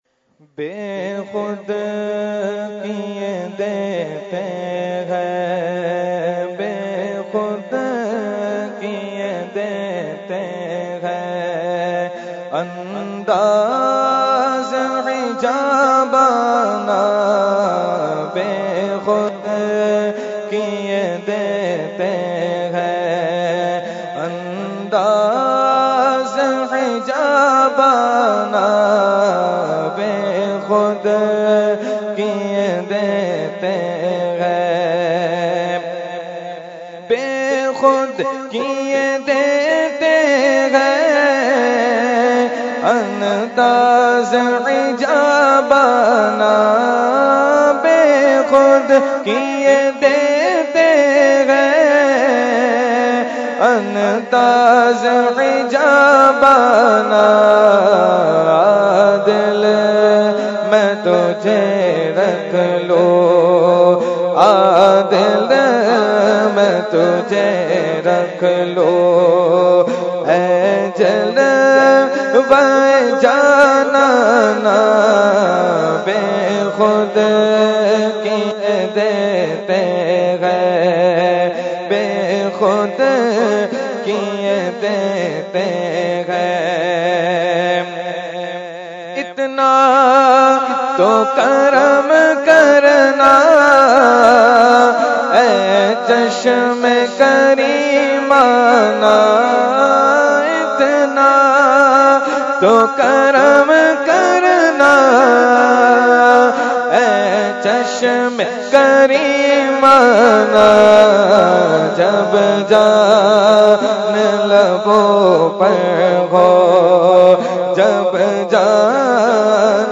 Category : Naat | Language : UrduEvent : Urs Qutbe Rabbani 2019